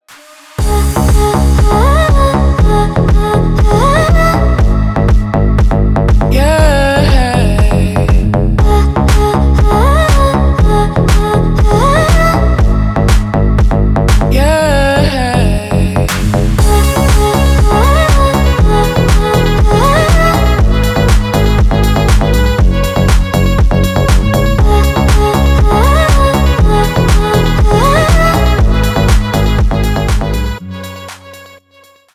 • Качество: 320, Stereo
deep house
приятные
качающие
красивый женский голос
нежные